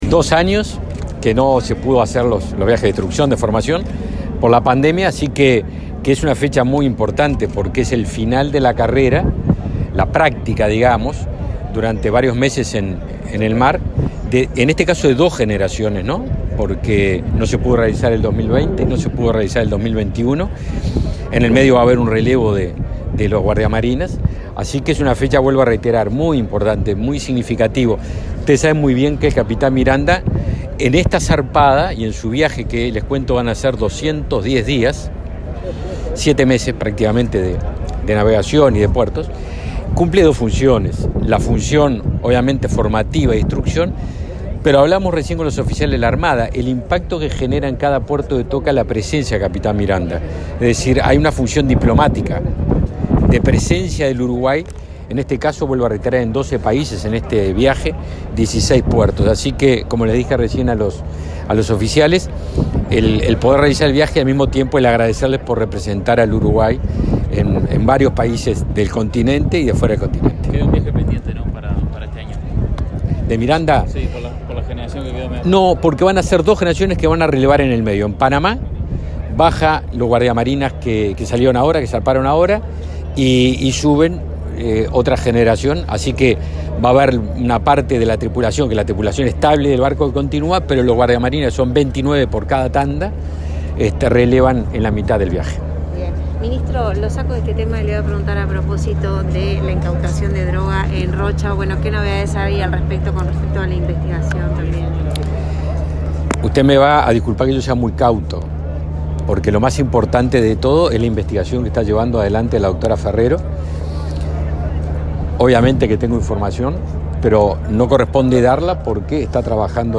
Declaraciones a la prensa del ministro Javier García